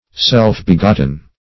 Search Result for " self-begotten" : The Collaborative International Dictionary of English v.0.48: Self-begotten \Self"-be*got"ten\, a. Begotten by one's self, or one's own powers.